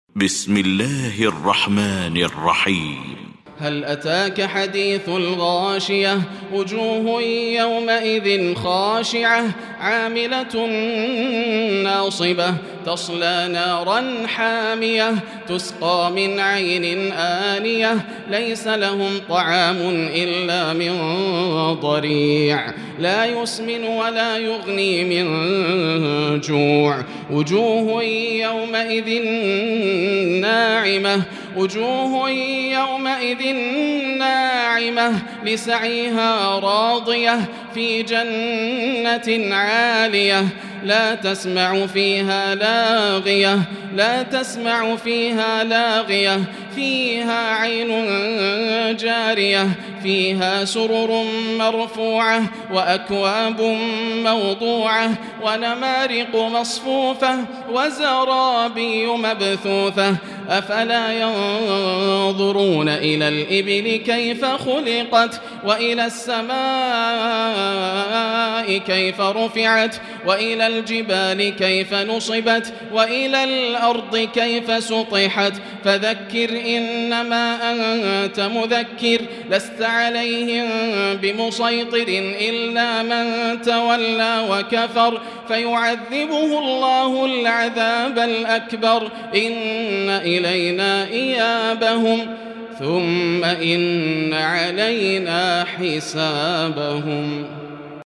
المكان: المسجد الحرام الشيخ: فضيلة الشيخ ياسر الدوسري فضيلة الشيخ ياسر الدوسري الغاشية